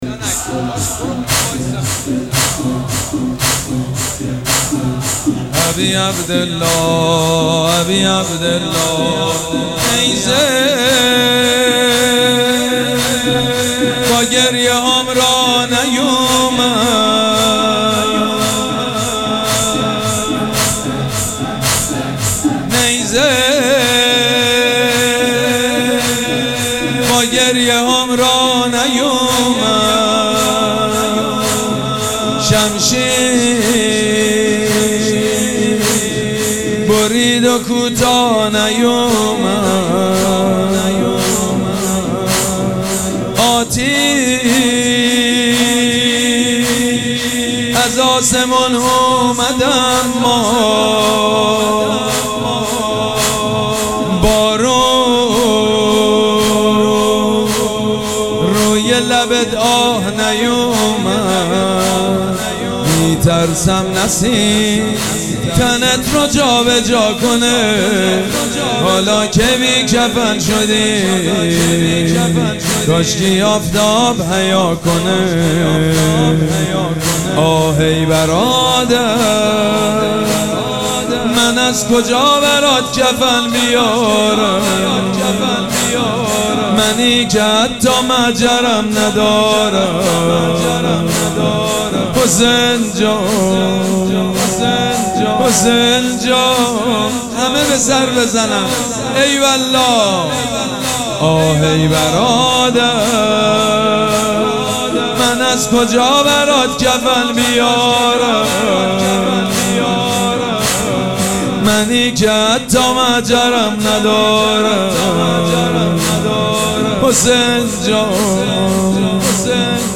شب پنجم مراسم عزاداری اربعین حسینی ۱۴۴۷
مداح
حاج سید مجید بنی فاطمه